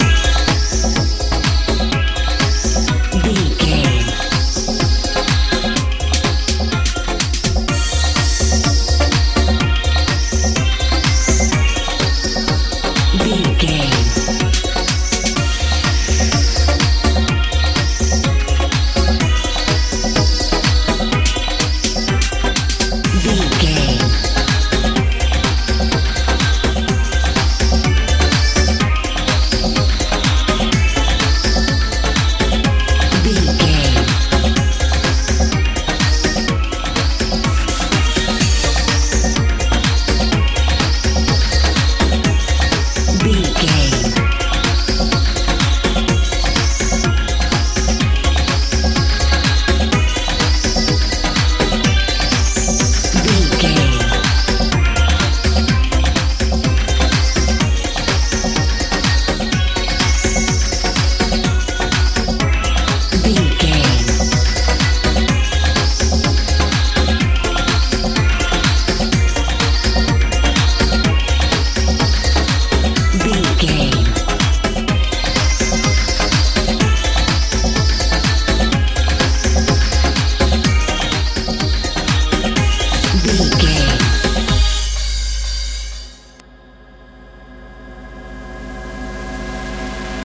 modern dance
Ionian/Major
A♭
energetic
dreamy
drums
bass guitar
synthesiser
80s
90s